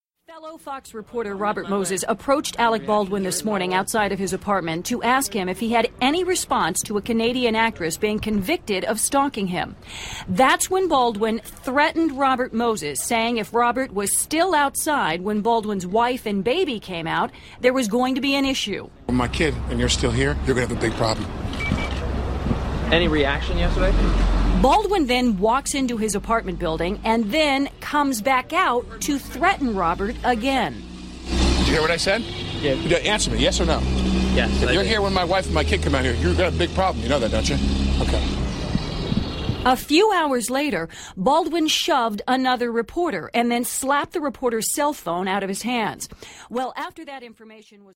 Alec Baldwin threatening the paparazzi